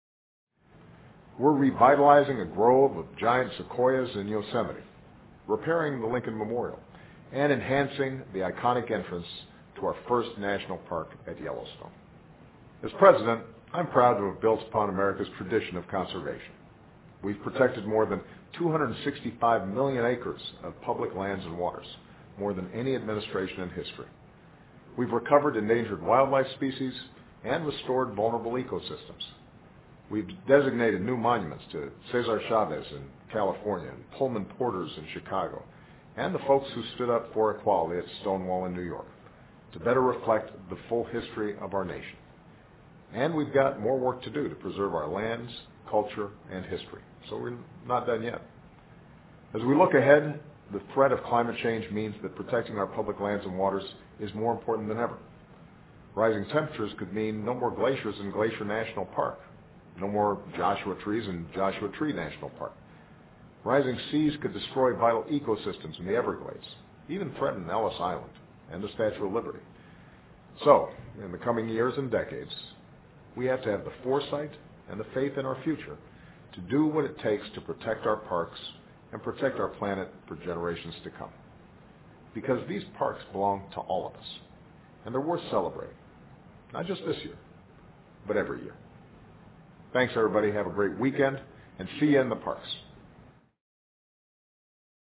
奥巴马每周电视讲话：总统庆贺国家公园管理局设立100 周年（02） 听力文件下载—在线英语听力室